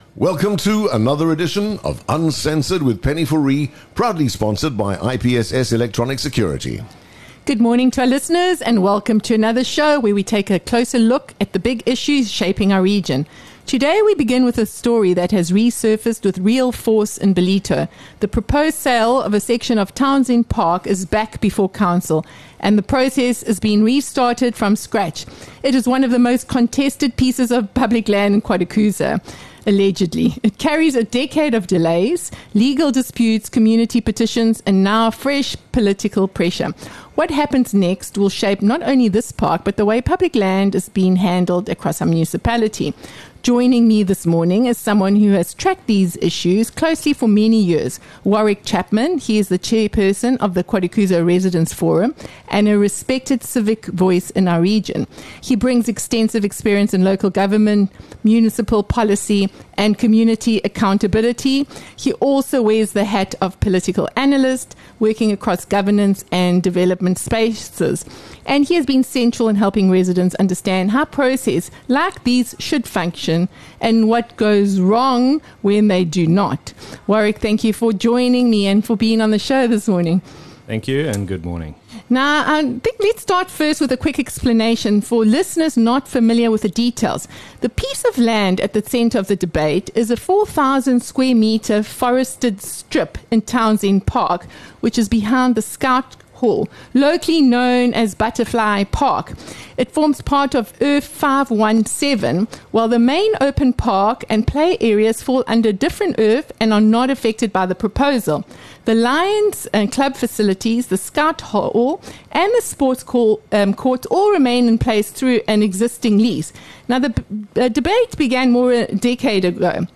Listen to the full conversation on Uncensored.